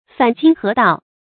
反经合道 fǎn jīng hé dào
反经合道发音